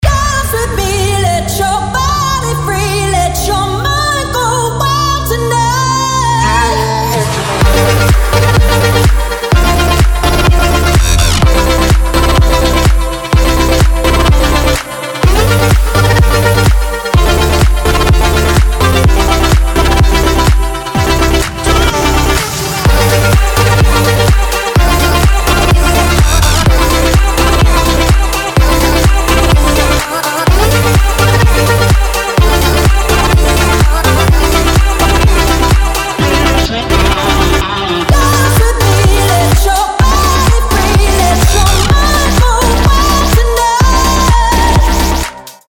• Качество: 320, Stereo
громкие
Electronic
EDM
future house